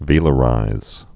(vēlə-rīz)